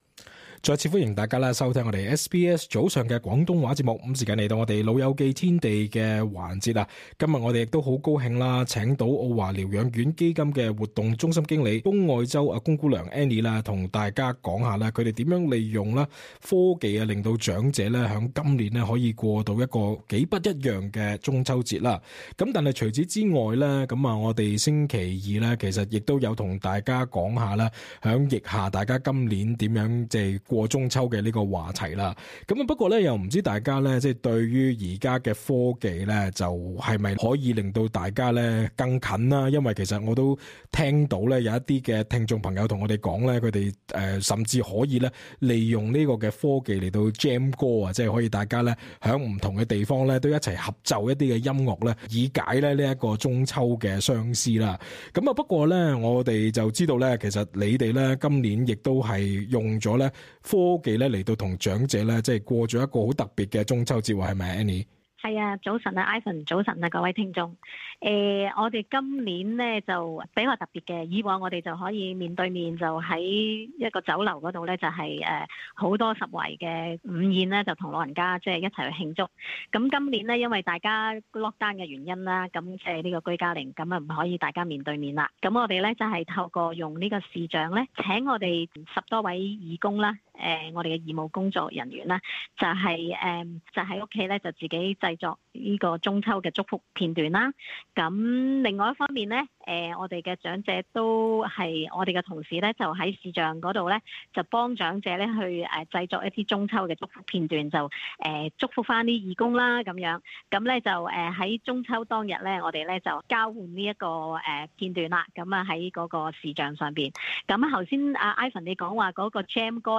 friday_talkback_can_technology_make_up_to_physical_contact_during_lockdown.mp3